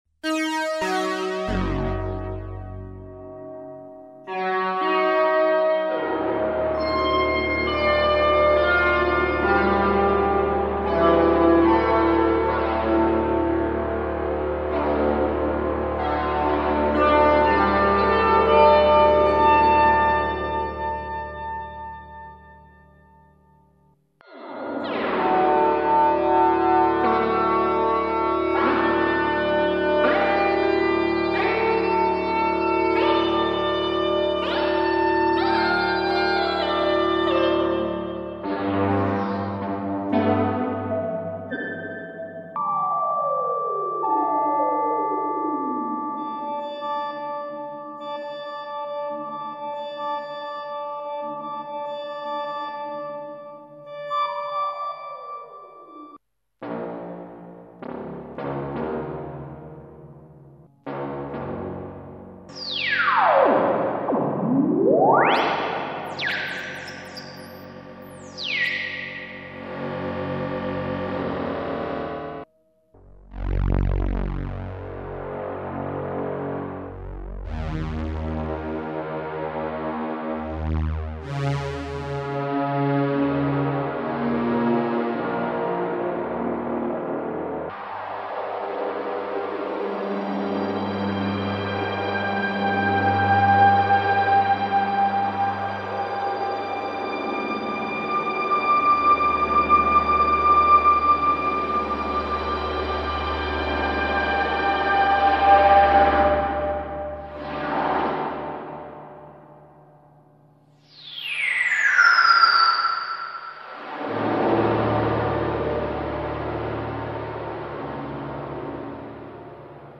Elka Synthex
Synthex withDRP-16